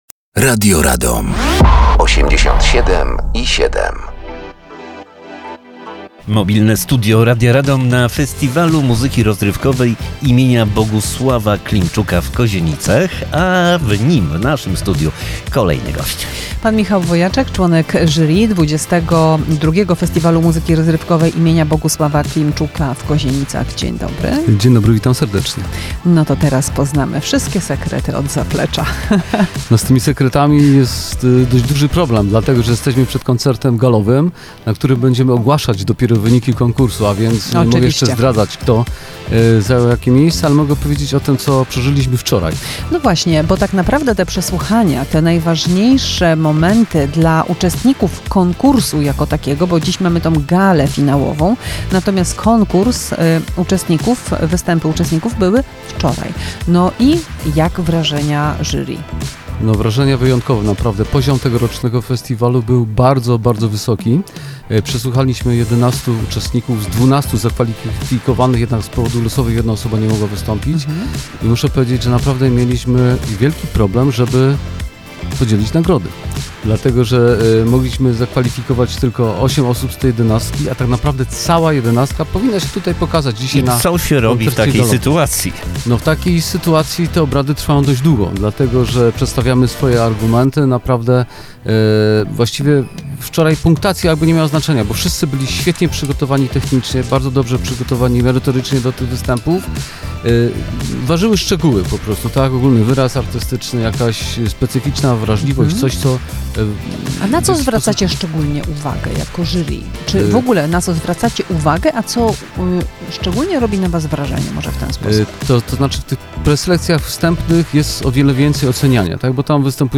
Mobilne Studio Radia Radom na XXII Festiwal Muzyki Rozrywkowej im. Bogusława Klimczuka.